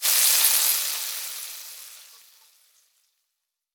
cooking_sizzle_burn_fry_06.wav